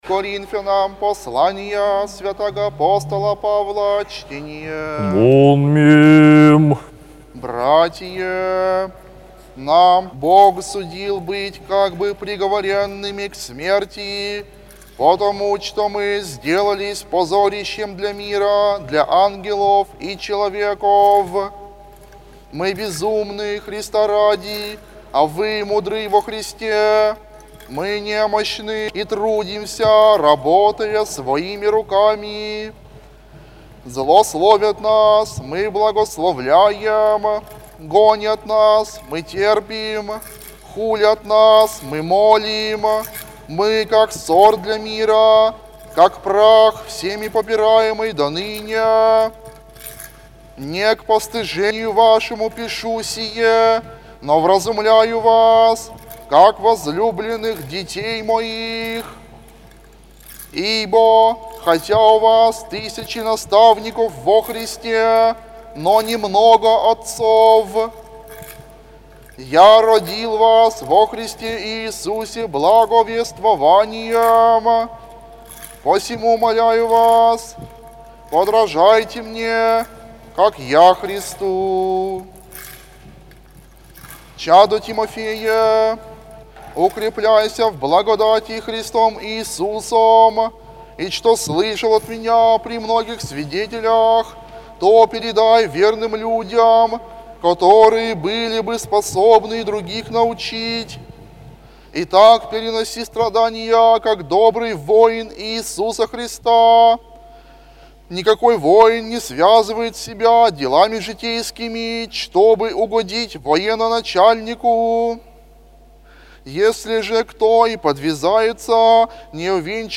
апостольское ЧТЕНИЕ